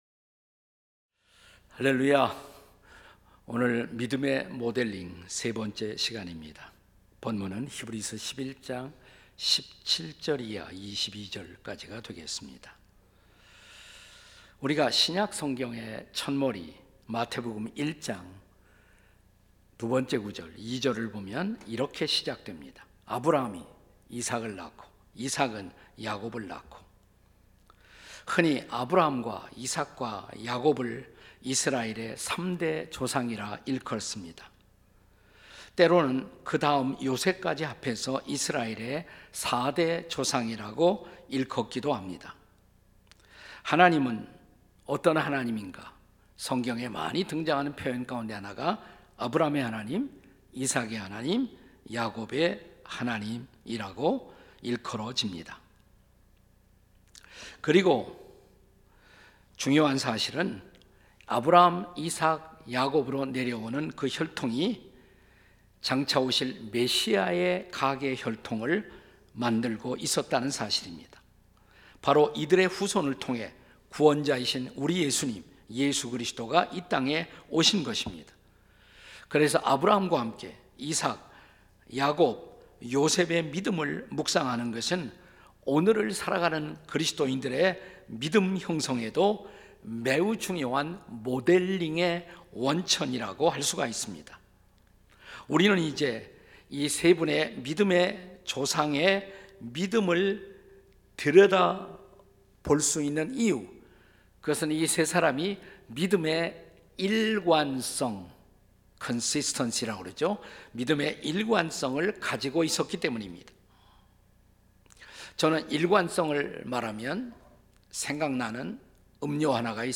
설교 : 주일예배 히브리서 - (16) 믿음의 모델링에 도전하라 3.